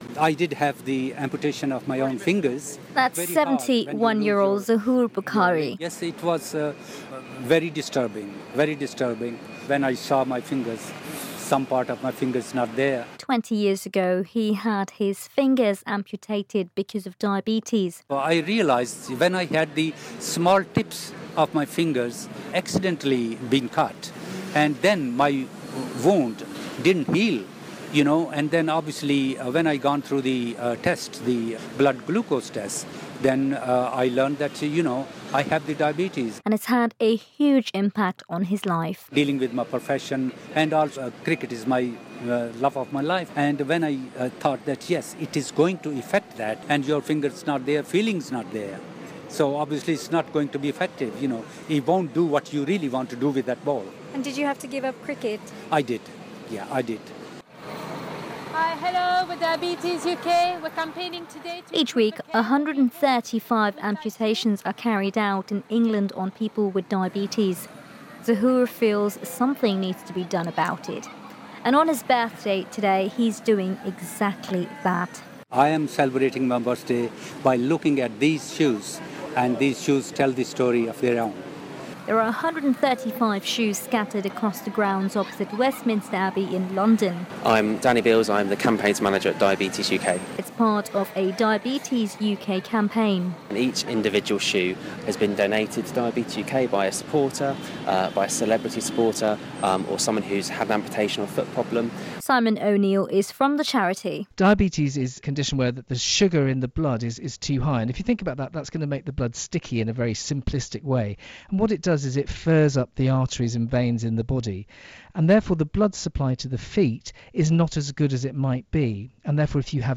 The number of people having limbs amputated as a result of diabetes has risen to 135 a week, Diabetes UK has warned. They say the rate is rising due to the huge increase in the number of people developing the condition, which is often associated with being overweight. Report for BBC Asian Network.